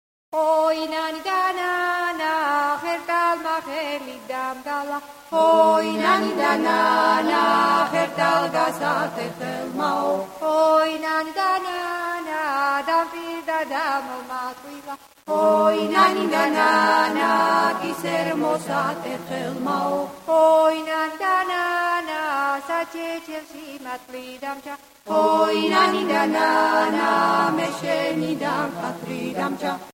- Traditional songs of Georgian women
work song